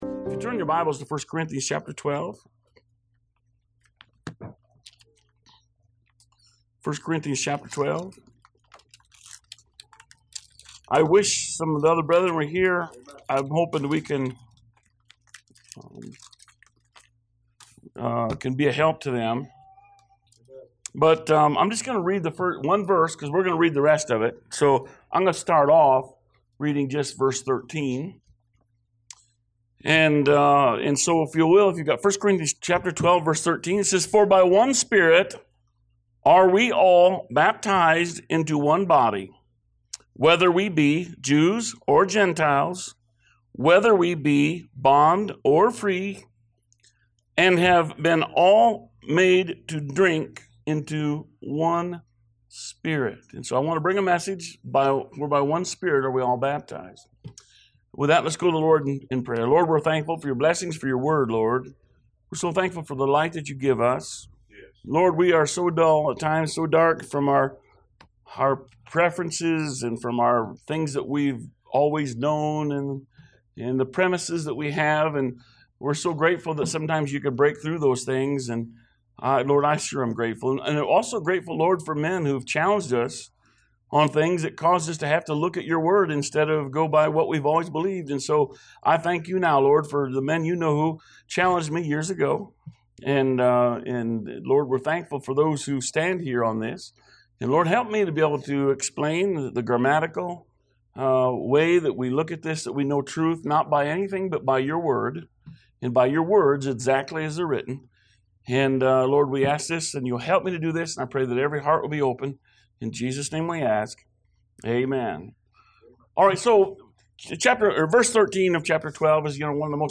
A message from the series "Stand Alone Messages."